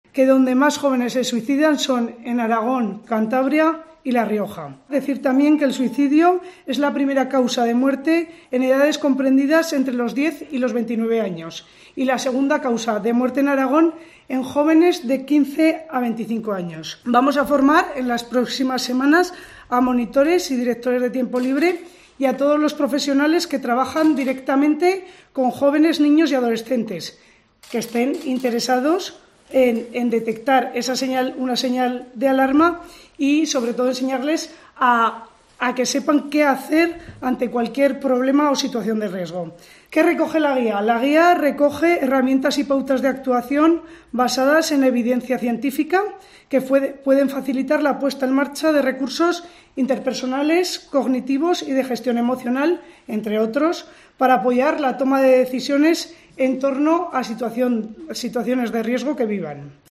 La directora del Instituto Aragonés de la Juventud, Cristina Navarro, explica los nuevos protocolos